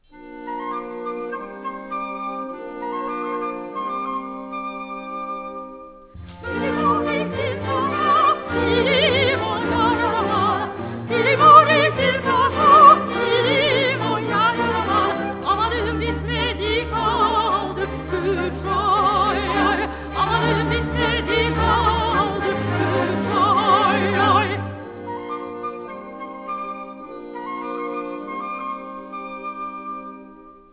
Duets